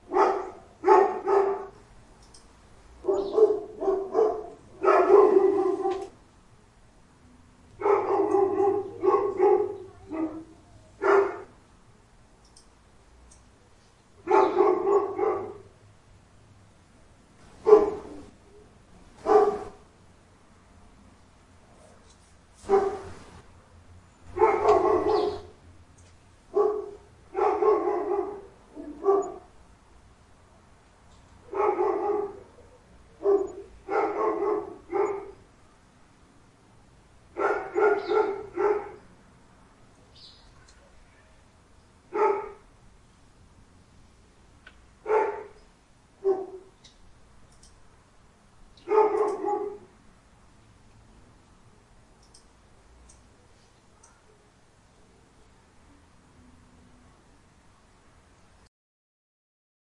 描述：在街对面记录我们邻居的吠狗。
Tag: 烦人 树皮 动物 郊区 宠物 吠叫 邻里